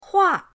hua4.mp3